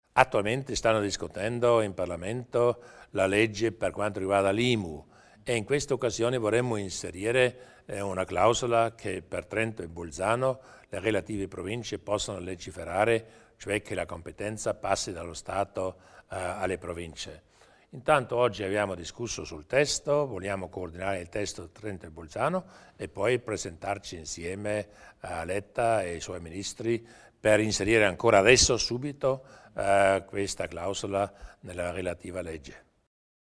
Il Presidente Durnwalder illustra le strategia in tema di finanza locale